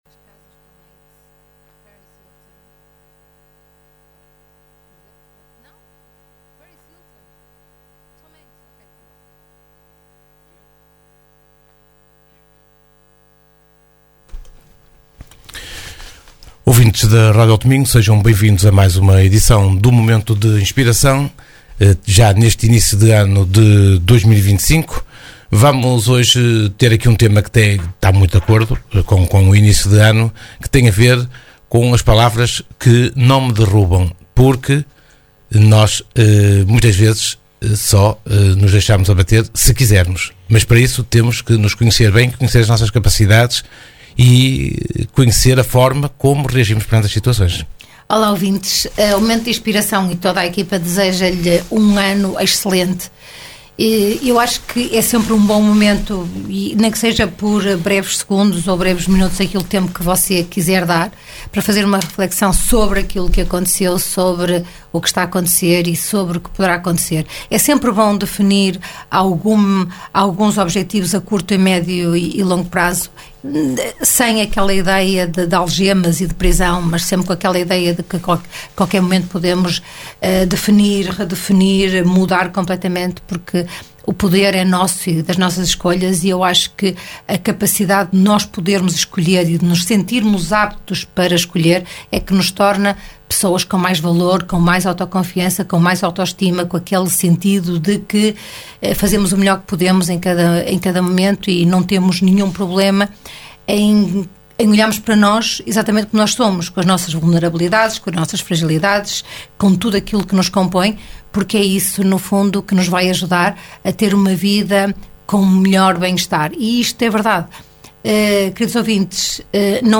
Momento de Inspiração (programa) | Segundas 22h.